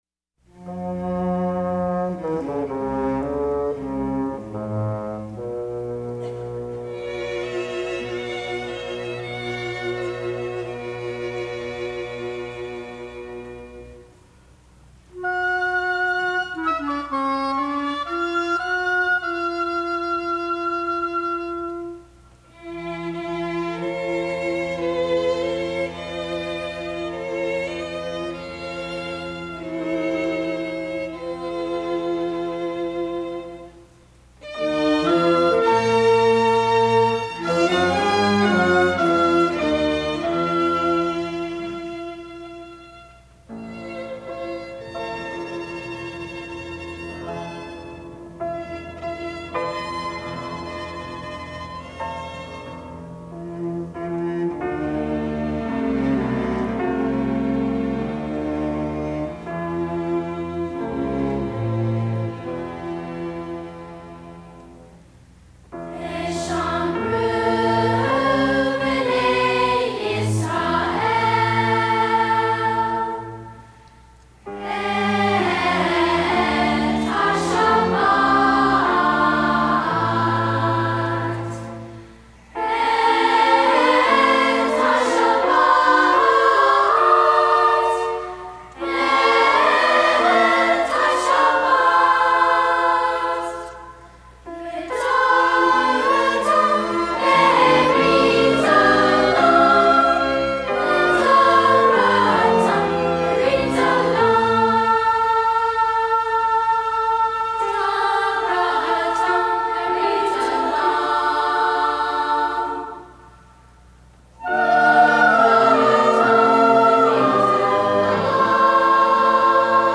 Voicing: SSA Choir